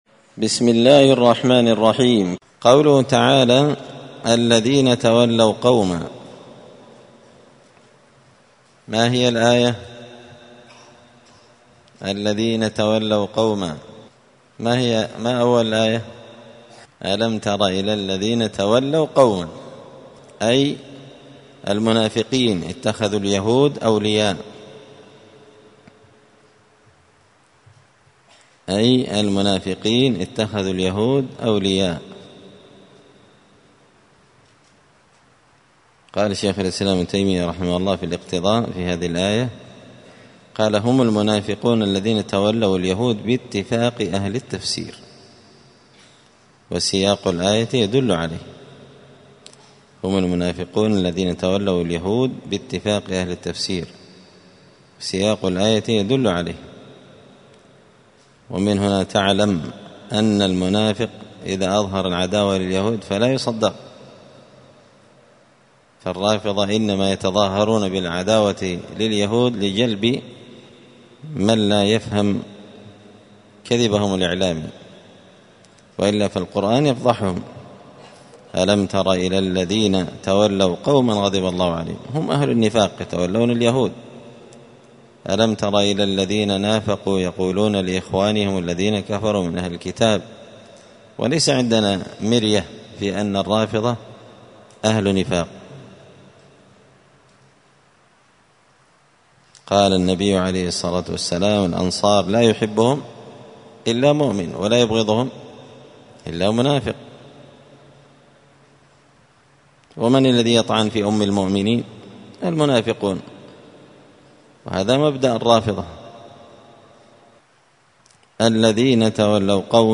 زبدة الأقوال في غريب كلام المتعال الدرس الرابع والعشرون بعد المائة (124)